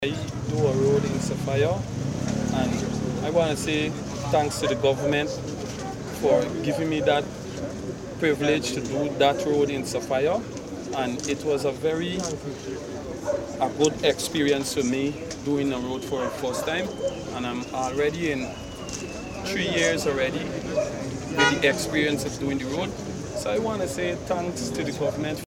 One resident shared his experience of working on a road construction project in Sophia, crediting the government for providing him the opportunity.